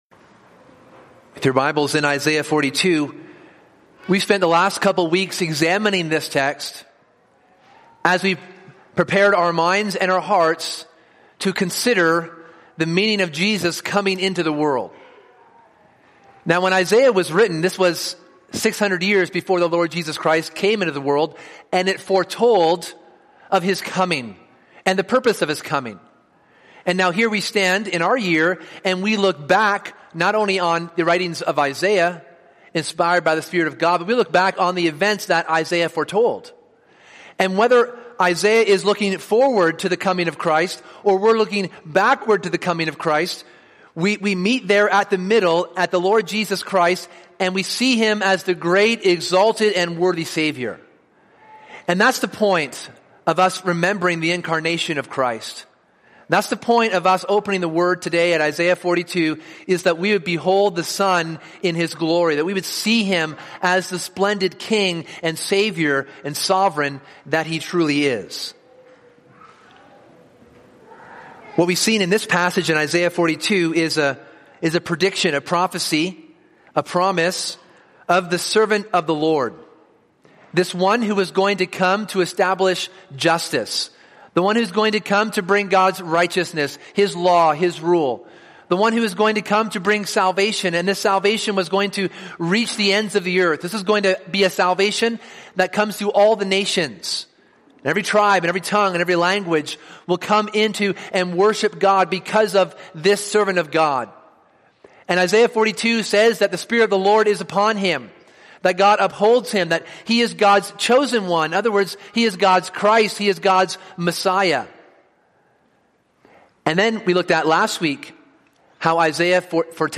In this third and final message examining Isaiah 42:1-12, this sermon considers the faithfulness of God to make and keep his promises.